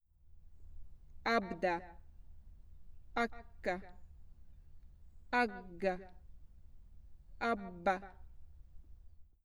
Artikuloituja äänteitä Lemin Haukkasaaren kalliomaalauksen edustalla, 27 metrin päässä kalliosta.